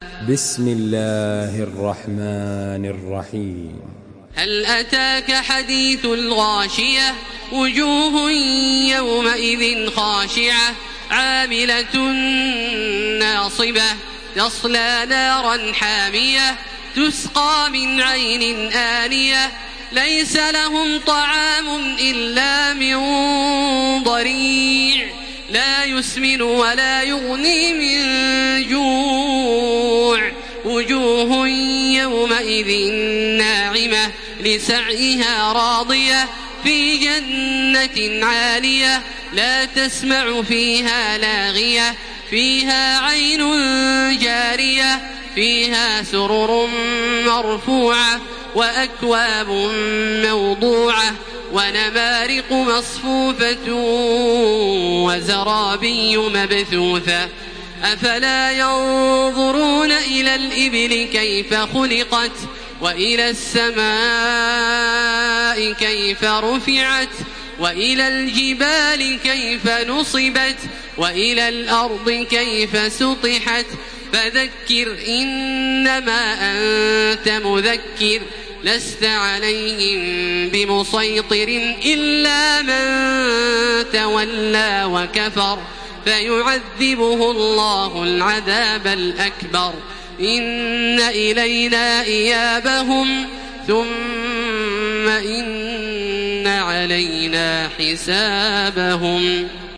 Makkah Taraweeh 1433
Murattal